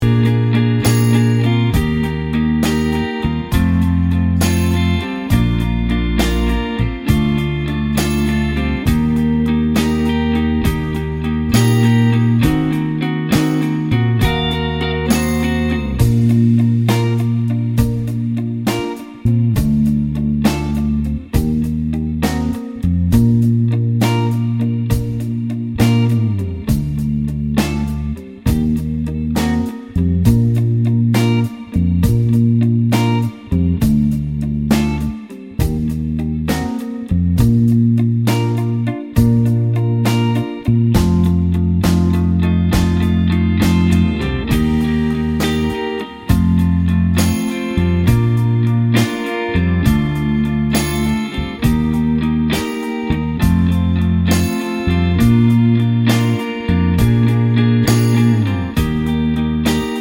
Duet Version Pop (2020s) 2:55 Buy £1.50